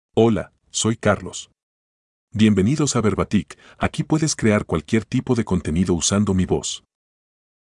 MaleSpanish (Honduras)
Carlos — Male Spanish AI voice
Carlos is a male AI voice for Spanish (Honduras).
Voice sample
Listen to Carlos's male Spanish voice.
Carlos delivers clear pronunciation with authentic Honduras Spanish intonation, making your content sound professionally produced.